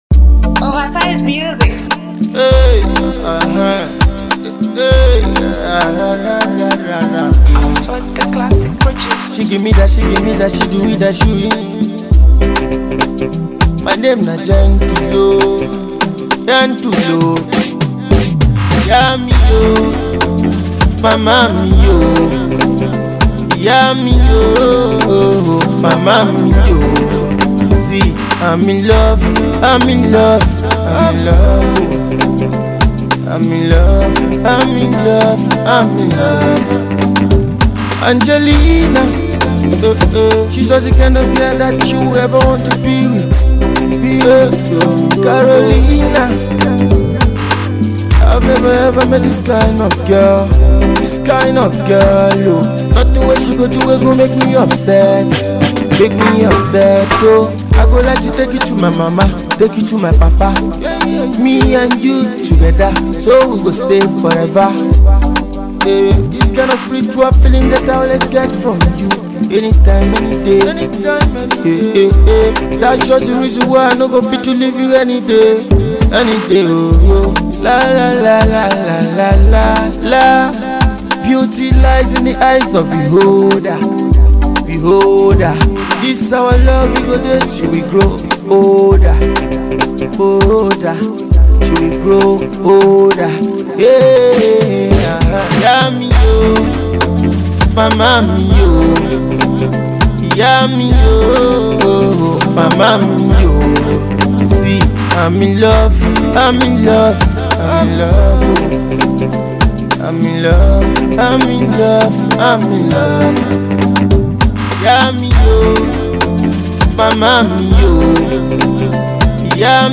mid tempo love song